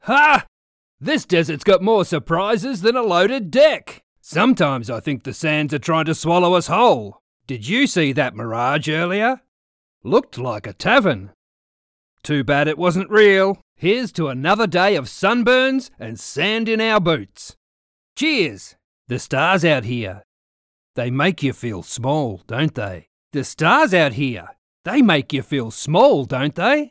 Male
My natural Australian accent is genuine and engaging.
Video Games
Character Voice
0110Bright_Australian_Video_Game_Character.mp3